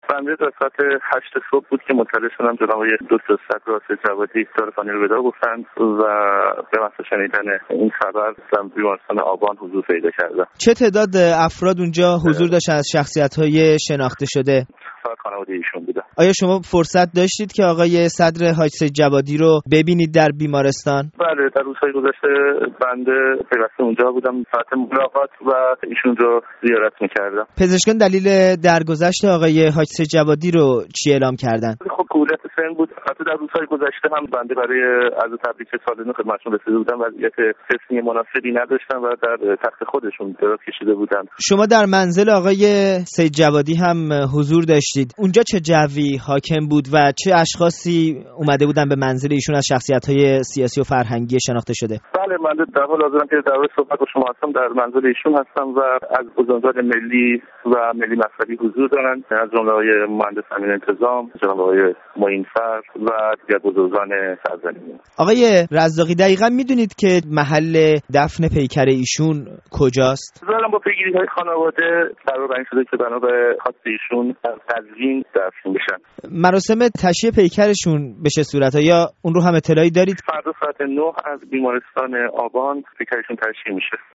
گفت‌و‌گوی